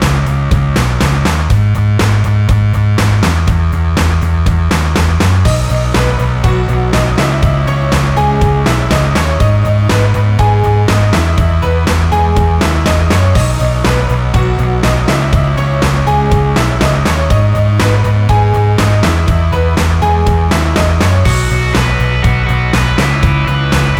no Backing Vocals Indie / Alternative 4:02 Buy £1.50